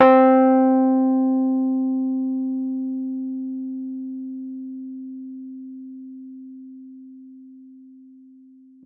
piano-sounds-dev
c3.mp3